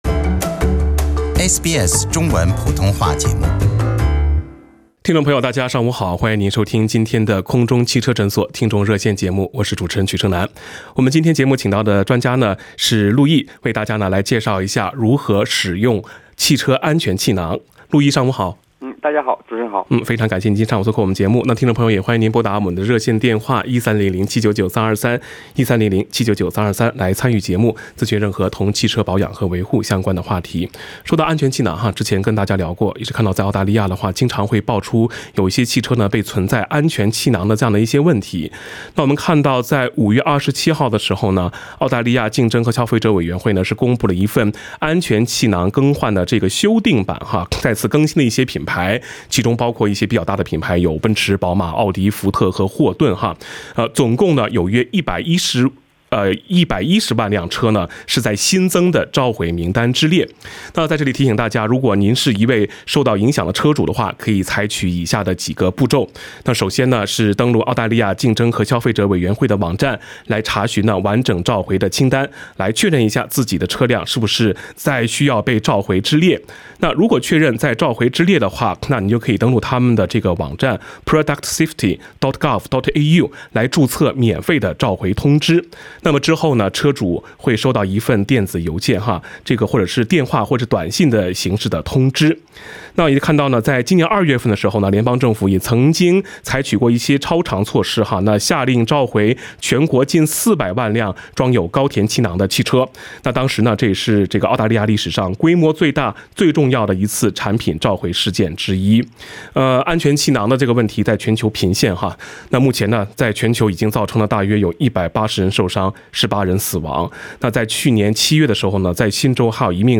《空中汽车诊所》听众热线节目